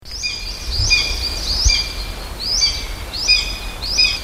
Myszołów - Buteo buteo
głosy